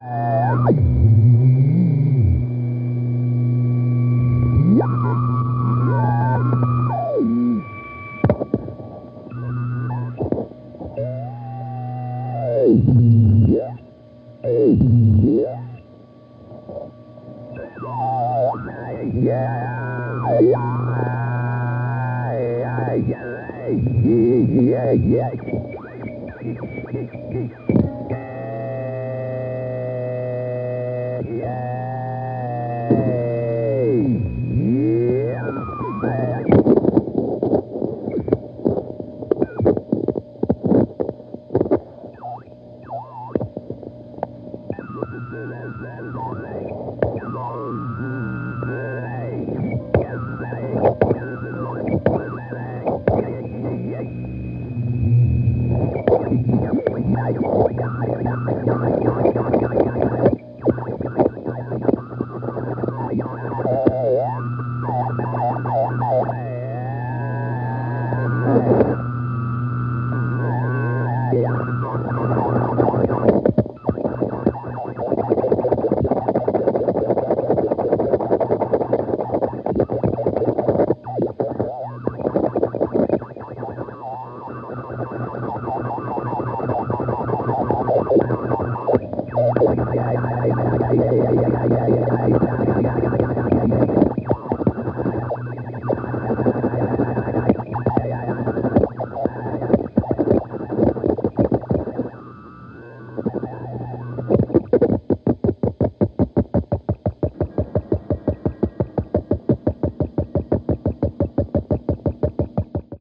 tape loops in contrapuntal collision.